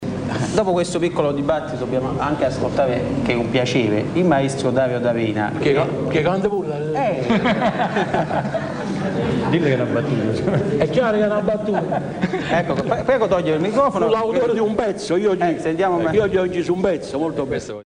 voce_merola_battuta.mp3